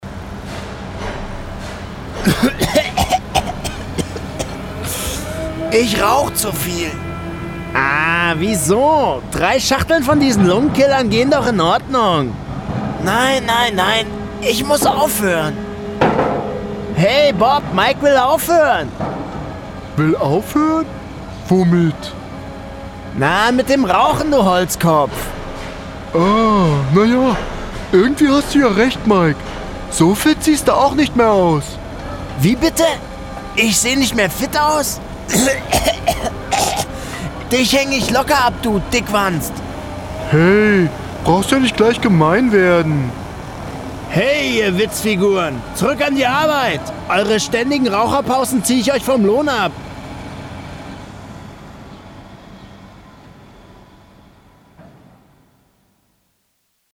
Sprecher für Werbespots, Dokumentationen, Synchron, Off, Hörspiel, Trickstimmen - sportliche, frische Stimme mit jugendlichem bis erwachsenem Charakter. Wandelbarer von seriös bis böse.
Sprechprobe: Sonstiges (Muttersprache):
german voice over artist